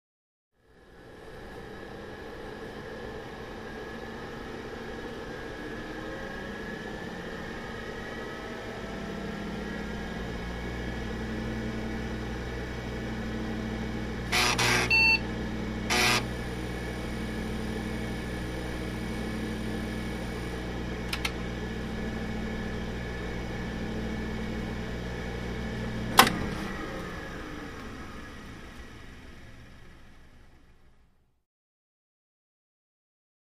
Vintage; On / Boot / Steady / Off; Vintage Computer Boot; Fan / 5.25 Drive Access / Beep / Drive Access / Floppy Access / Key Click / Power Off / Fan Spin Down, Close Perspective.